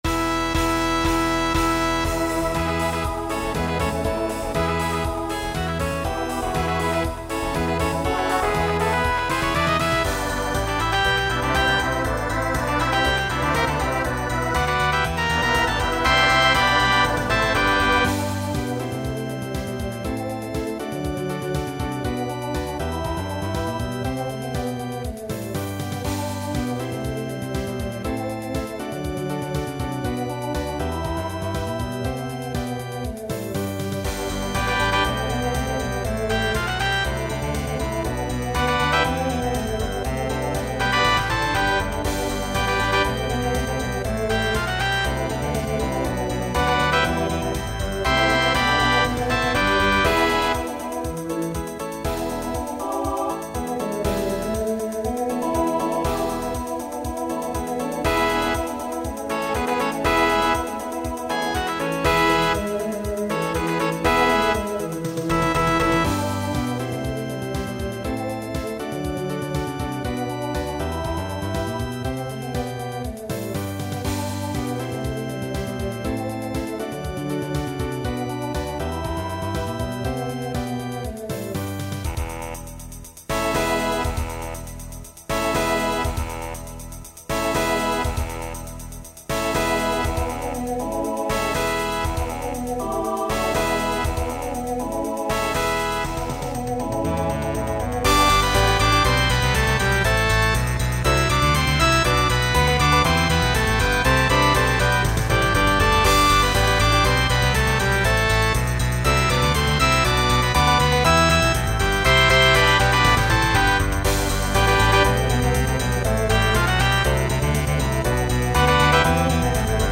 Voicing TTB Instrumental combo Genre Disco , Pop/Dance